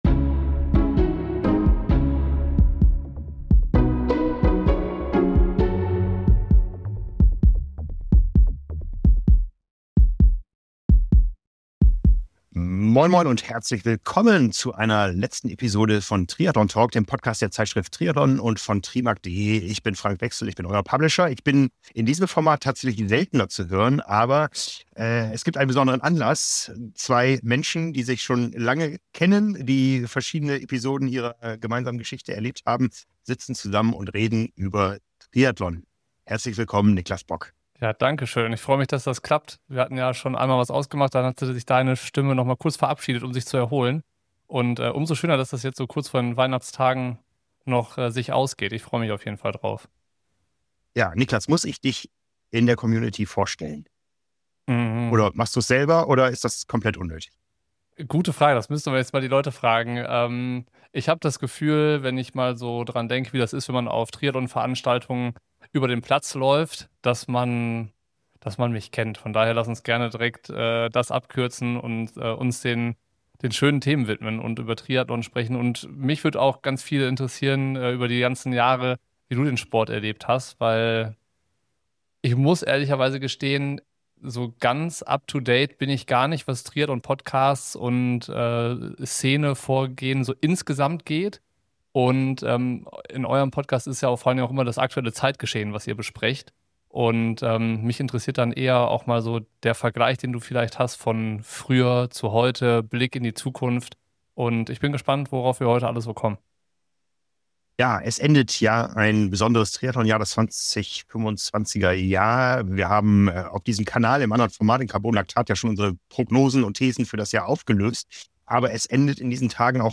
Wie sieht der Ex-Profi den Sport heute? Ein Gespräch über die Vergangenheit, Gegenwart und Zukunft des Triathlons.